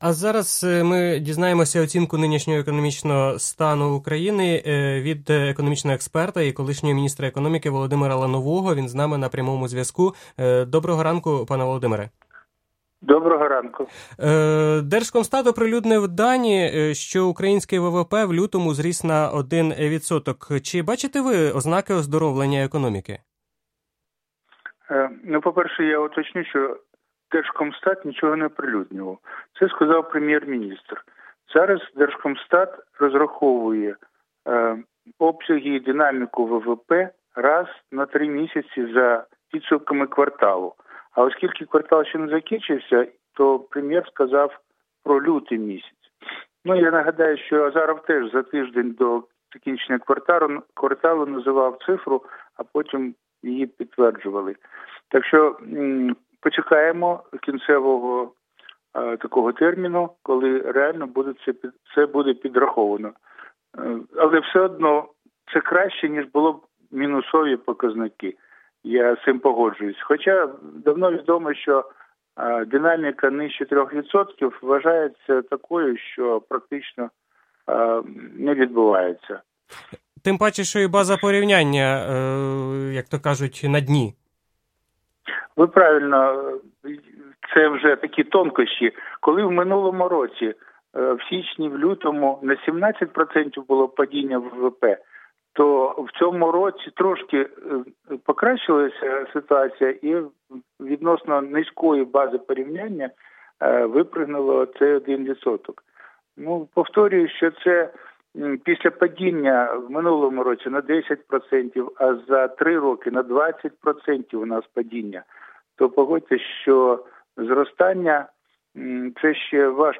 Київ – Зростання ВВП на 1%, про яке звітував прем’єр-міністр України Арсеній Яценюк, є свідченням радше позитивної тенденції, аніж реального зростання економіки, вважає колишній міністр економіки Володимир Лановий. Адже на тлі минулорічних падінь показники мали б бути вищими в усіх секторах, натомість, зокрема, в сільськогосподарському секторі, і досі спостерігаються мінусові показники, сказав експерт в ефірі Радіо Свобода.